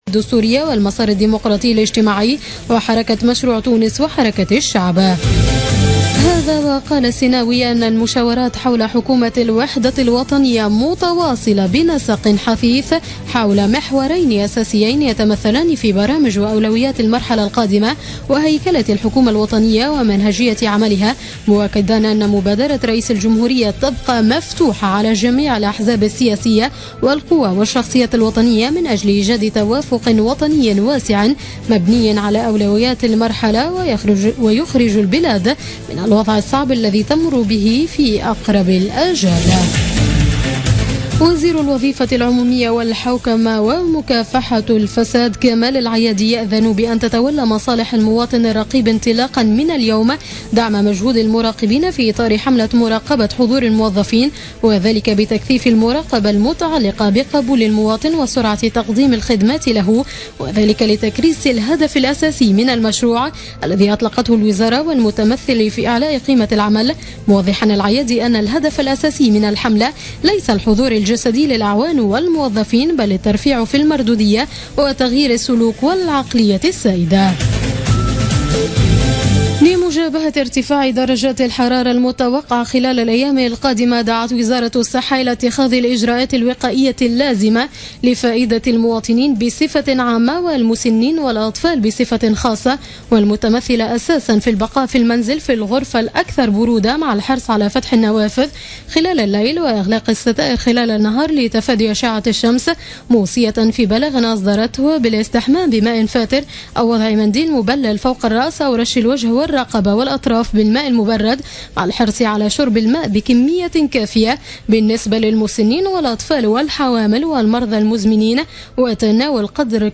نشرة أخبار منتصف الليل ليوم الاربعاء 15 جوان 2016